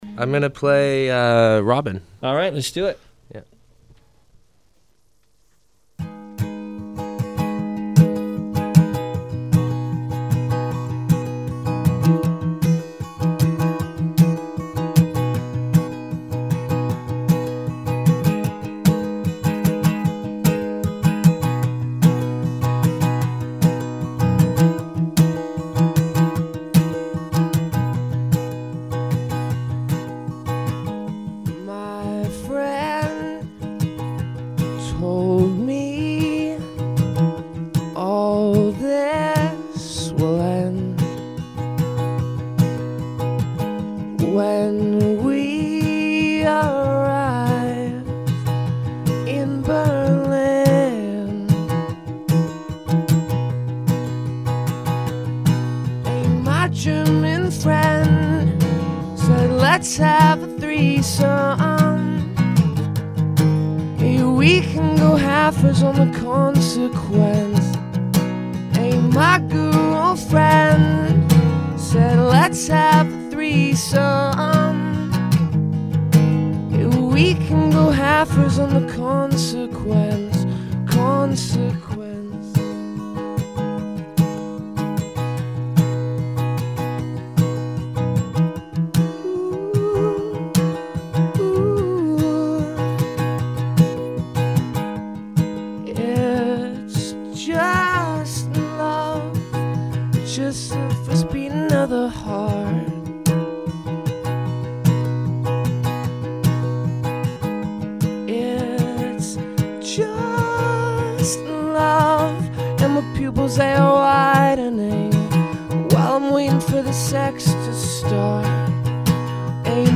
acoustic take
(live at Zone)